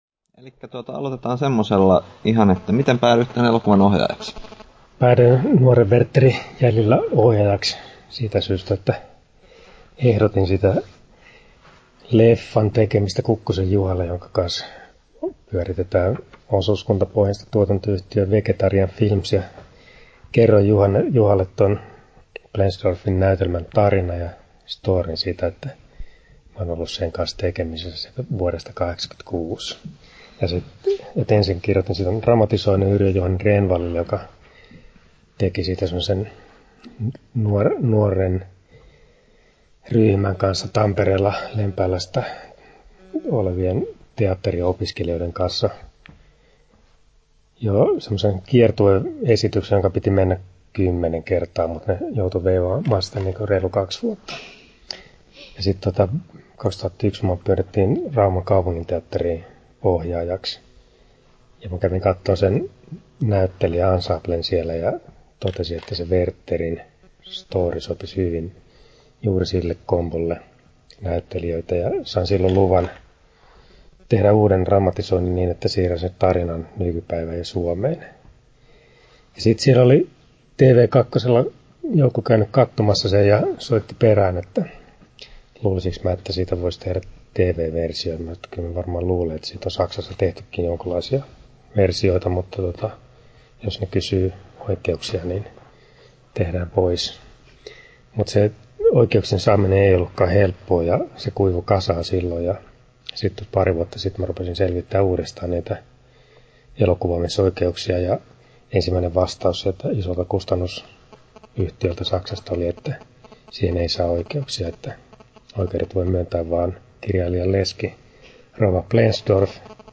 haastattelu
13'42" Tallennettu: 19.11.2013, Turku Toimittaja